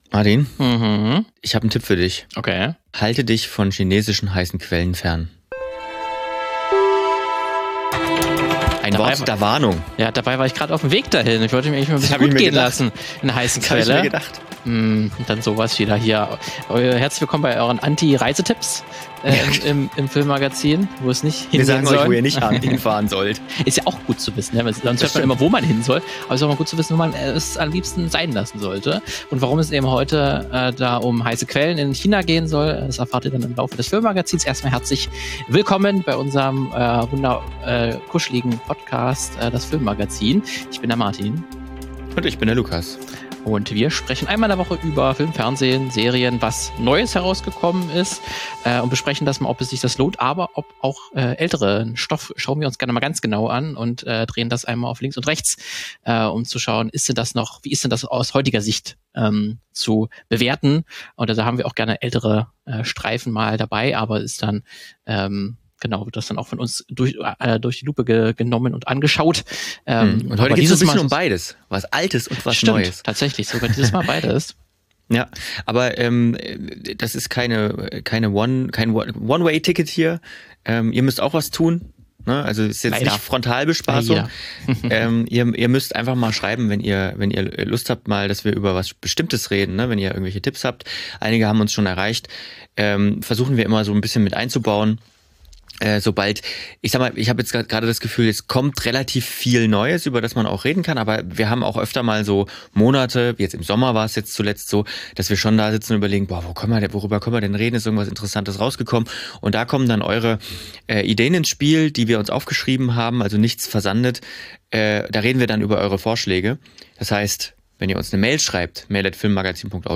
In dieser Sonderfolge sprechen wir mit drei Filmschaffenden aus unterschiedlichen Bereichen der Branche und erfahren wie sie die aktuelle Lage meistern.